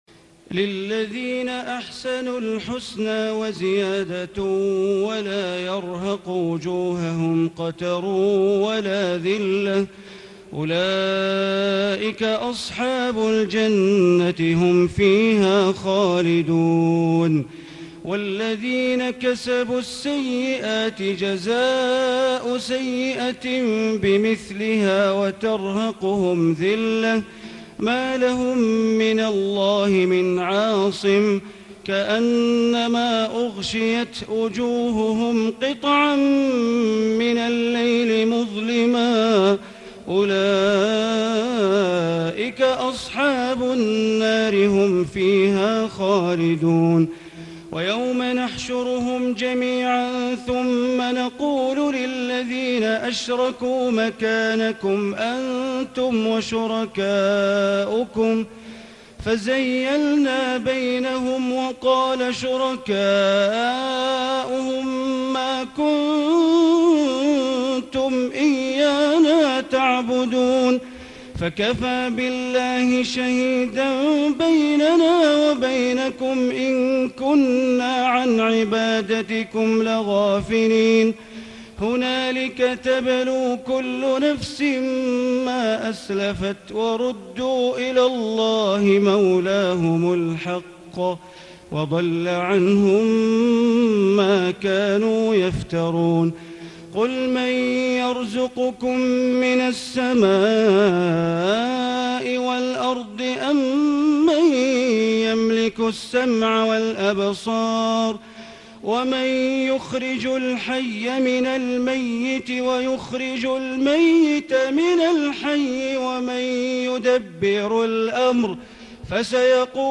الليلة العاشرة من رمضان عام ١٤٣٨ من سورة يونس آية ٢٦ إلى نهاية السورة > تراويح ١٤٣٨ هـ > التراويح - تلاوات بندر بليلة